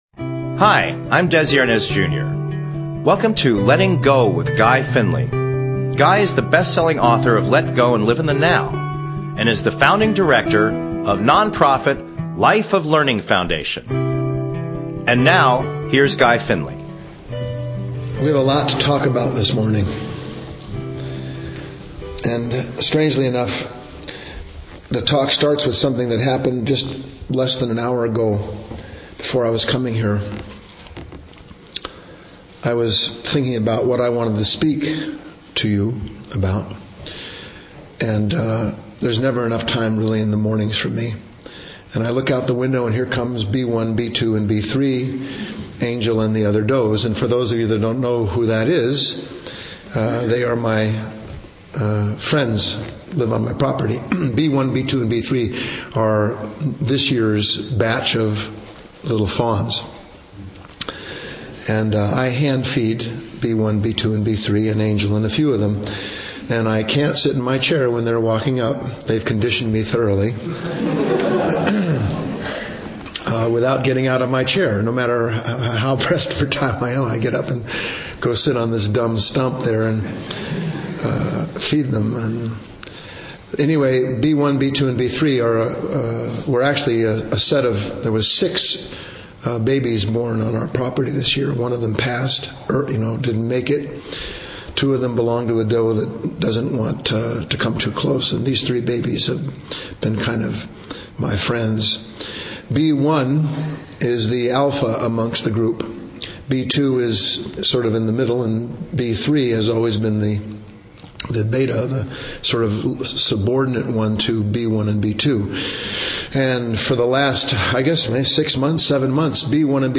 Talk Show Episode, Audio Podcast, Letting_Go_with_Guy_Finley and Courtesy of BBS Radio on , show guests , about , categorized as